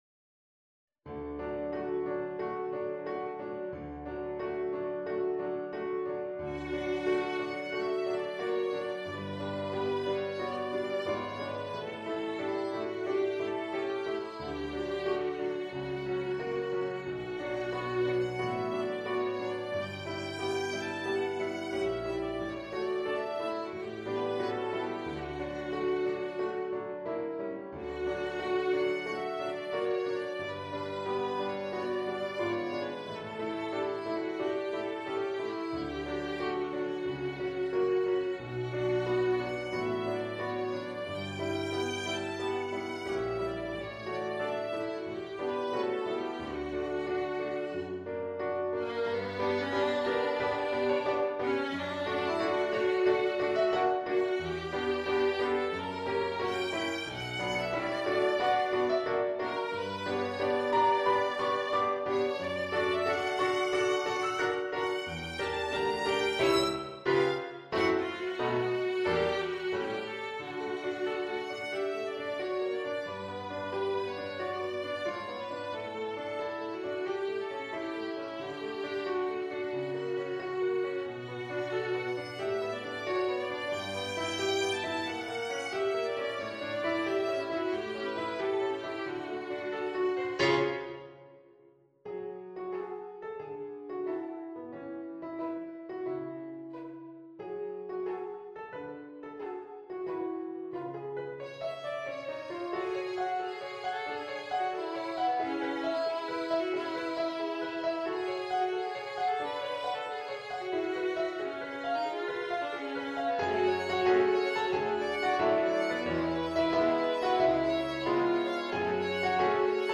SOLO PIANO
• Piano Solo
• Género: Banda Sonora Videojuego